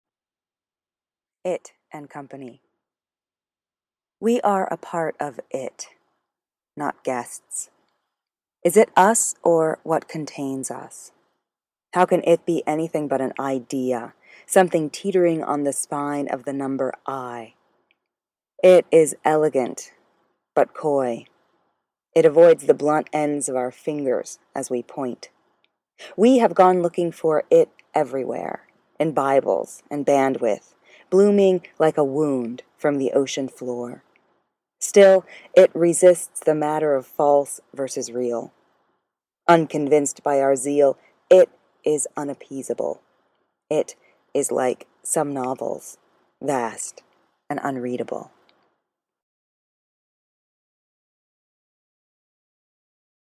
Tracy K. Smith reads "It & Co." from her third poetry collection, Life on Mars, published by Graywolf Press in May.